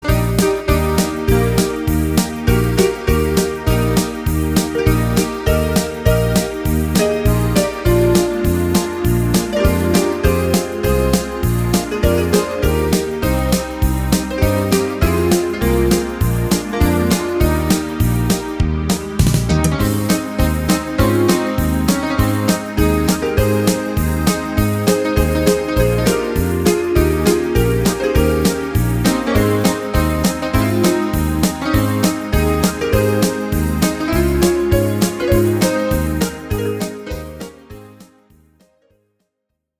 Rubrika: Pop, rock, beat
- směs - foxtrot
Karaoke